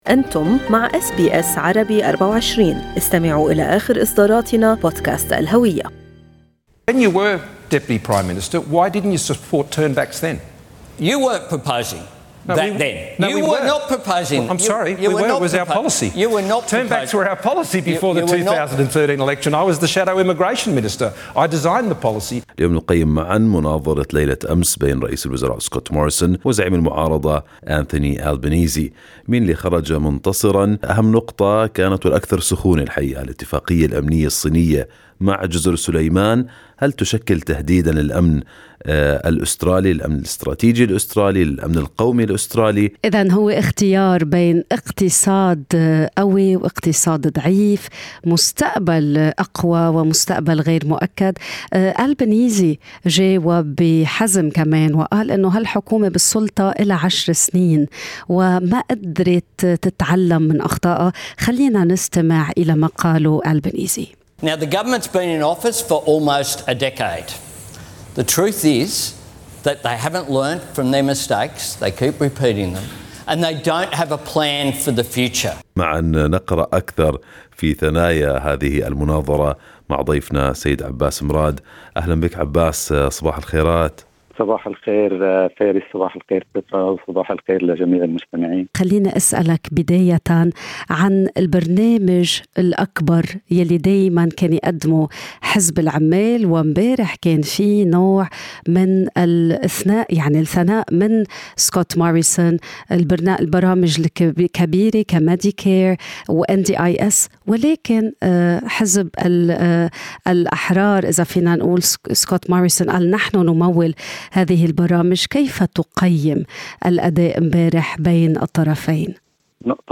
في مقابلة تحليلية